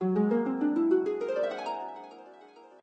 magic_harp_1.ogg